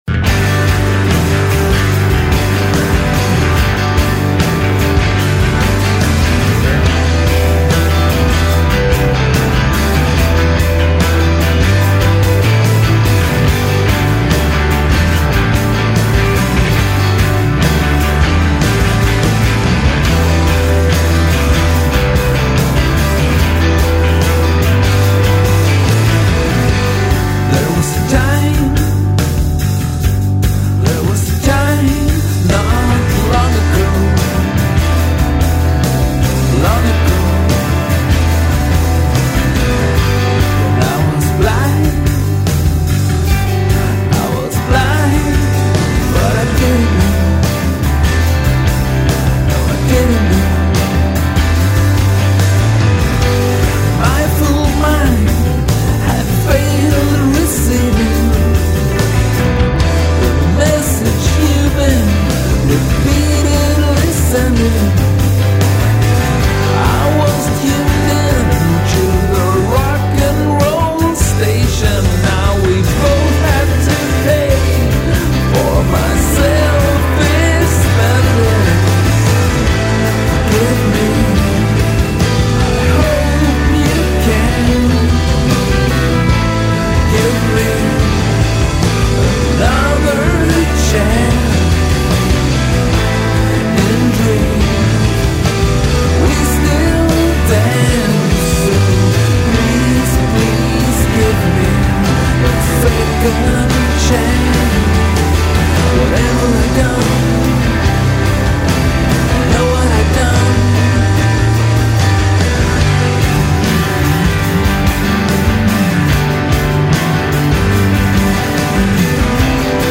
indie-rock quartet